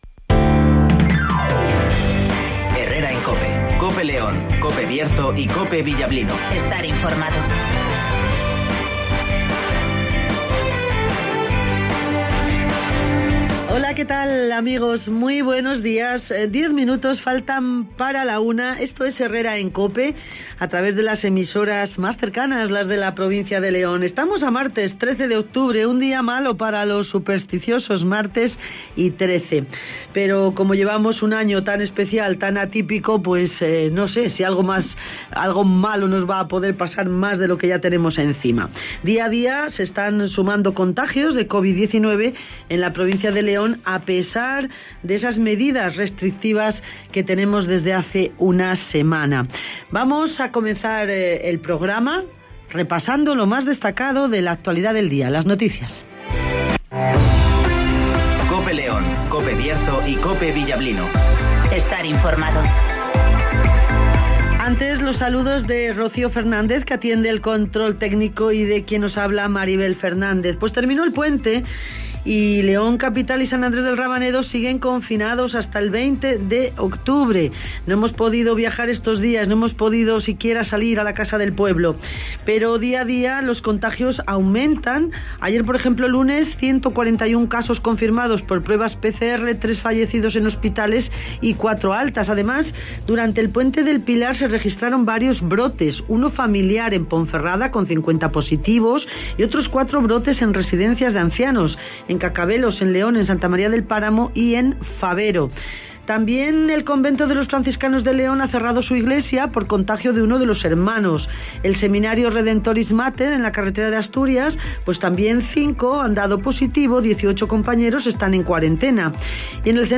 -Avance informativo